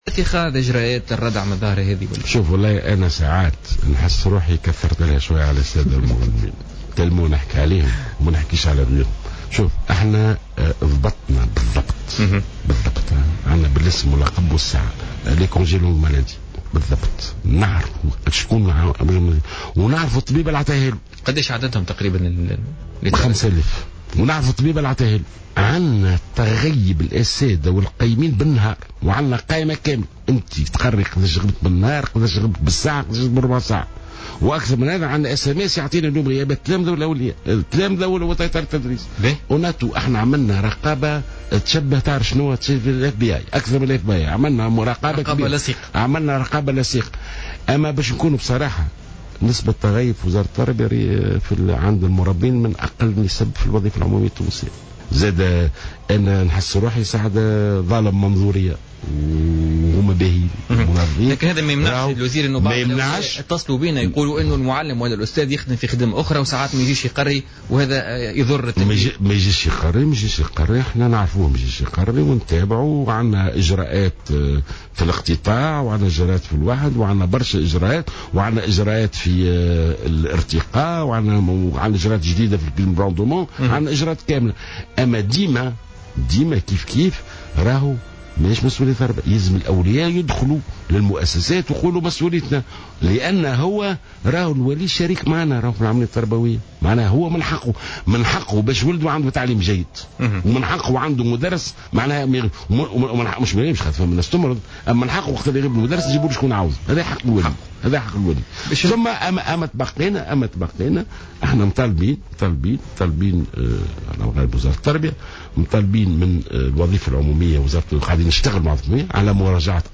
وقال جلول ضيف برنامج "بوليتيكا" اليوم الجمعة إن الوزارة لها جميع المعطيات الدقيقة بخصوص هذه الغيابات، مشيرا إلى أن الوزارة تتابع هذا الموضوع وتقوم برقابة لصيقة تشبه ""FBI""، حسب قوله.